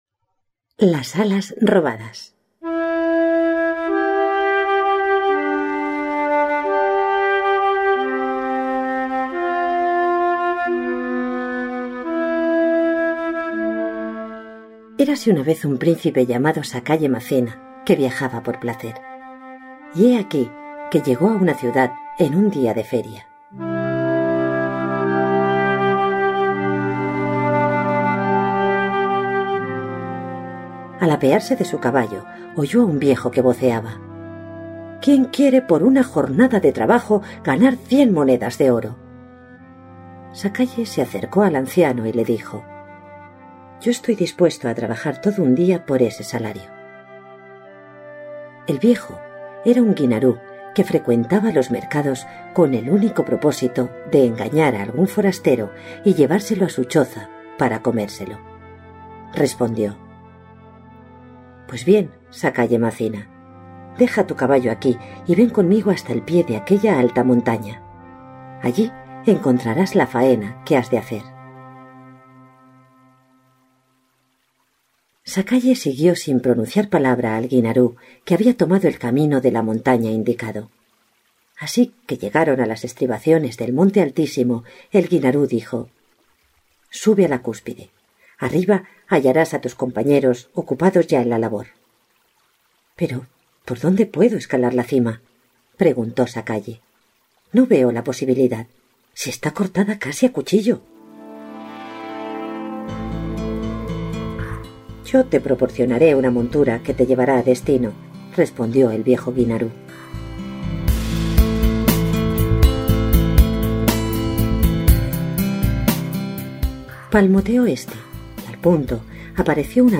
Audiolibro gratis